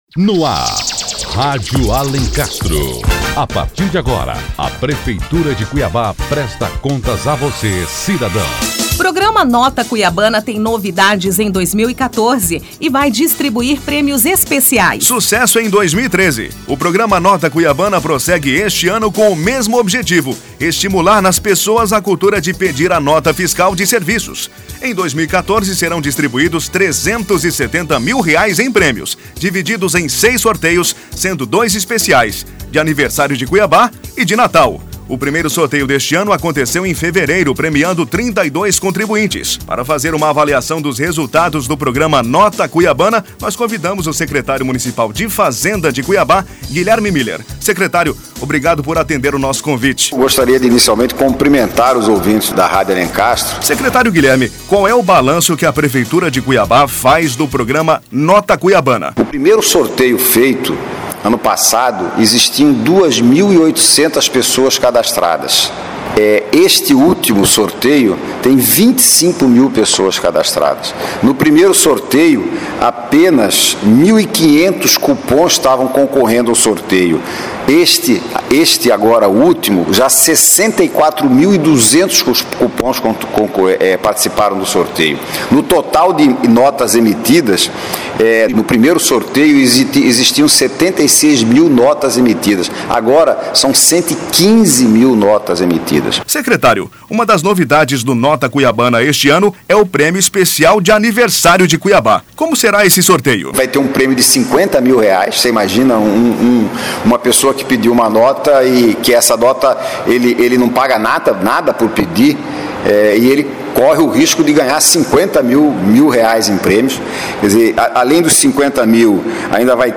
O Secretário Municipal de Fazenda, Guilherme Muller, faz uma avaliação do programa Nota Cuiabana em 2013 e comenta as mudanças para 2014.